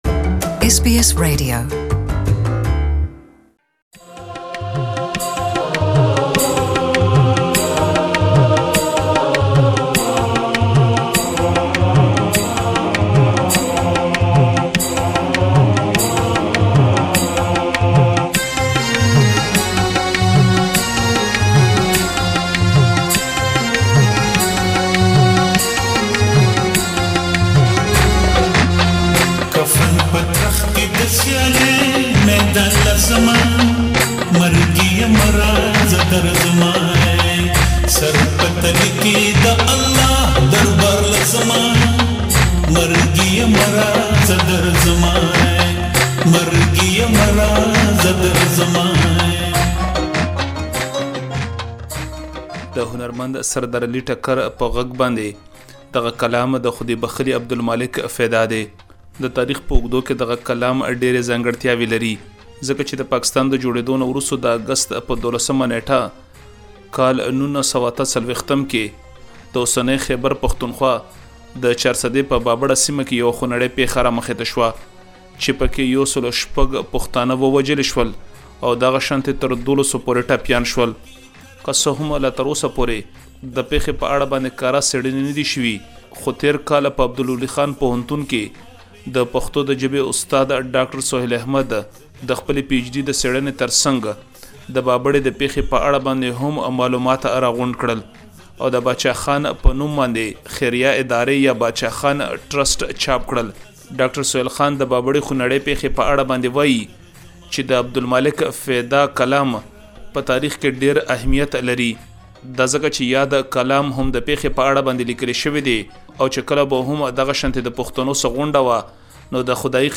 For more details, please listen to the full report in Pashto.